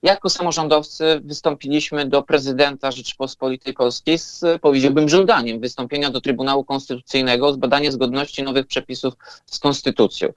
– Ta ustawa jest niezgodna z konstytucją – mówi prezydent Ełku.